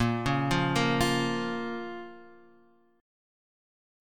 Bbm Chord